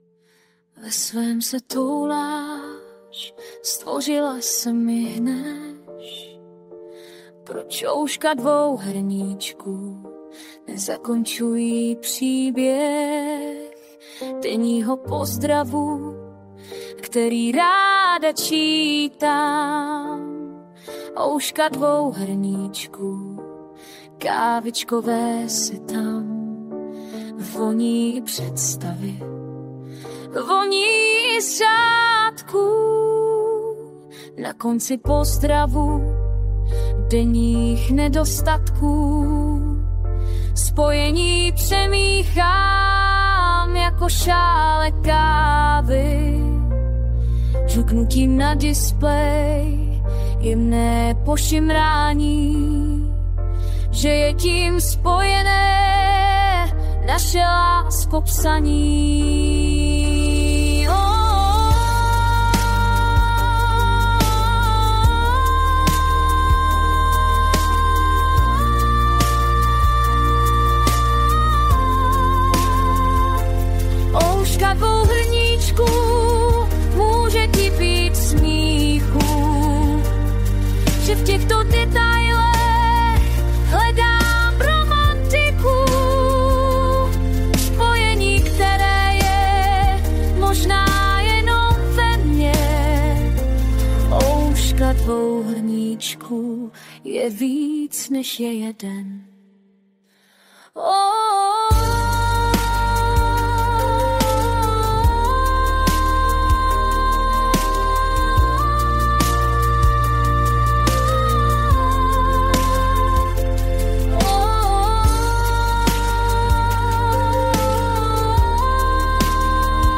Hudba a zpěv AI
Poprvé jsem zkusila použít volně dostupný program pro zhudebnění a zpěv u mého textu.